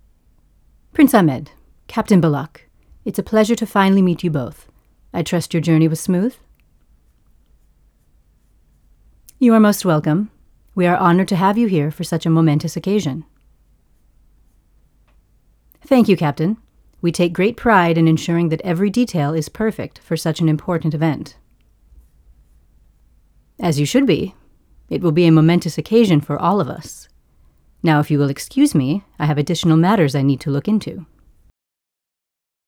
"Admiral Merietsy" role on The Journeys podcast series
Standard American English
Middle Aged